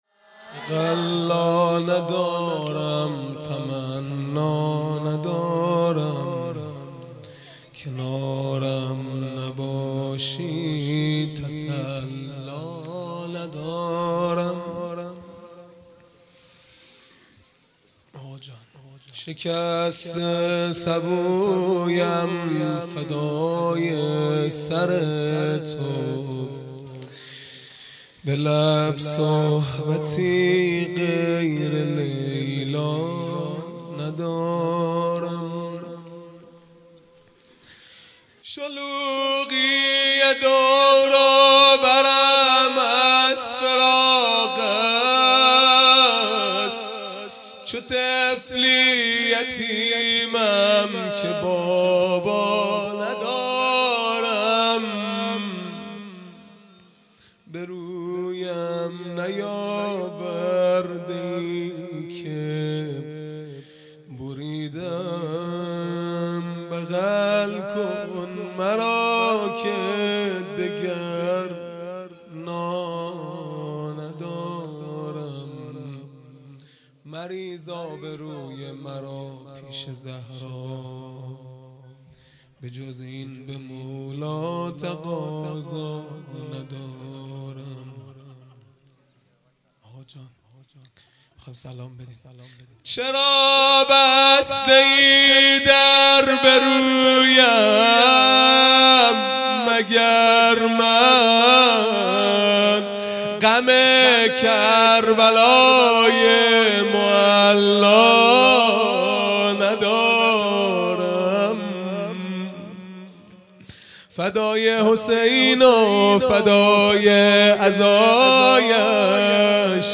مناجات پایانی | تقلا ندارم تمنا ندارم | سه شنبه ۲6 مرداد ۱۴۰۰
دهه اول محرم الحرام ۱۴۴۳ | شب تاسوعا | سه شنبه ۲6 مرداد ۱۴۰۰